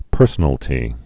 (pûrsə-nəl-tē)